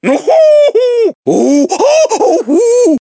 One of Donkey Kong's voice clips in Mario Kart 7